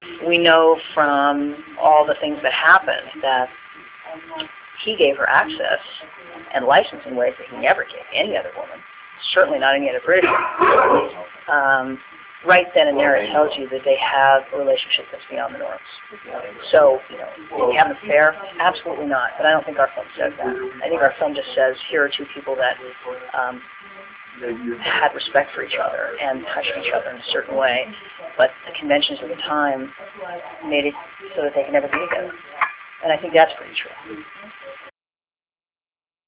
Interview Highlights (Audio)